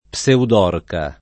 pseudorca [ p S eud 0 rka ] s. f. (zool.)